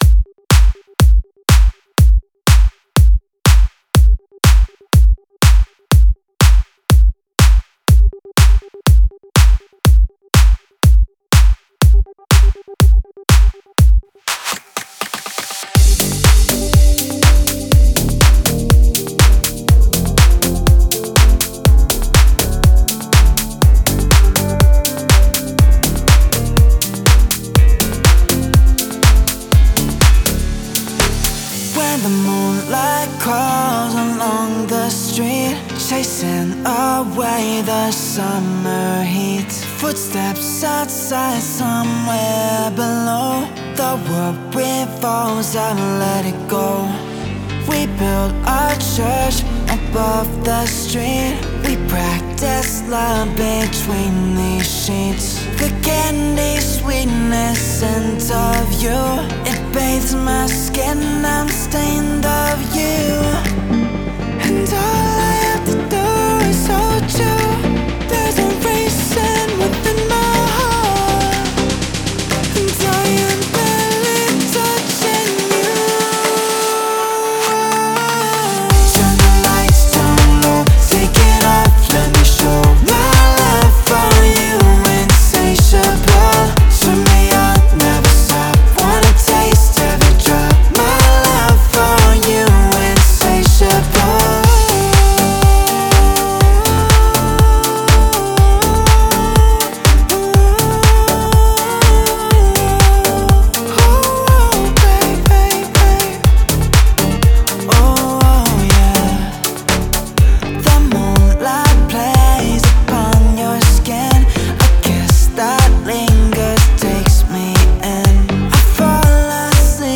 клубные ремиксы